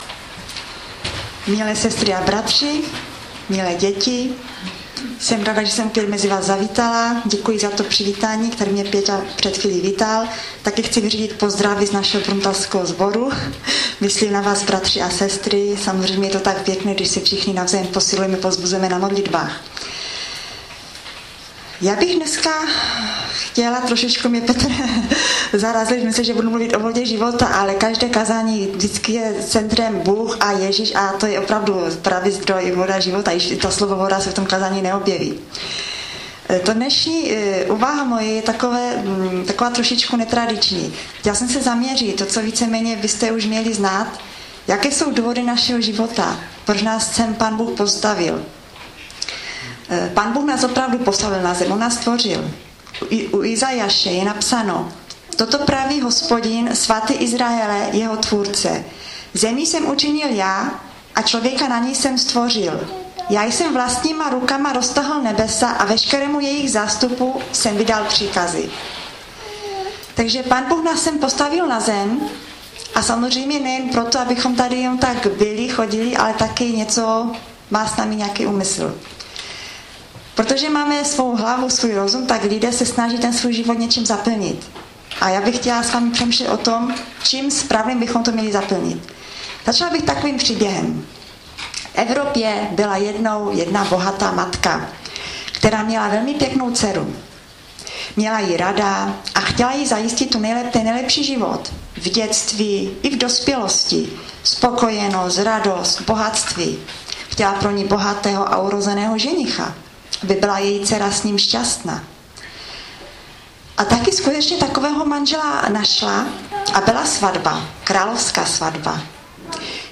Kázání sboru CASD Vrbno pod Pradědem